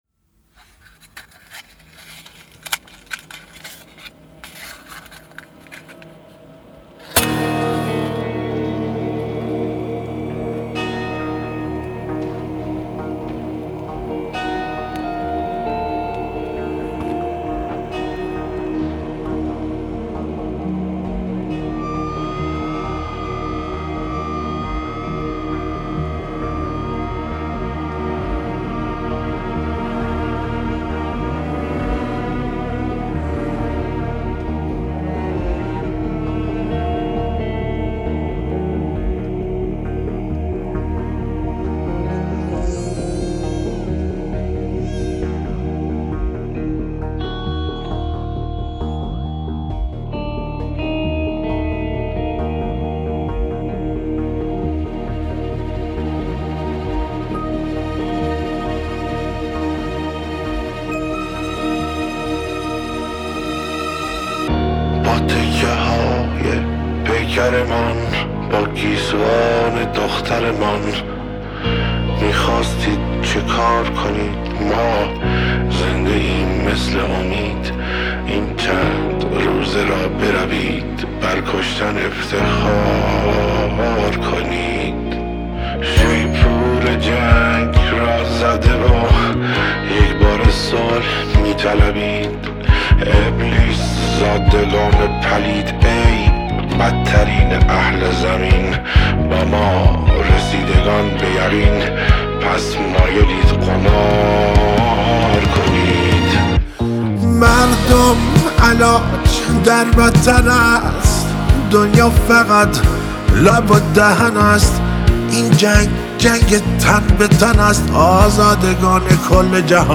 گیتار الکتریک
خواننده، ترانه‌سرا و آهنگساز مشهور ایرانی
موسیقی حماسی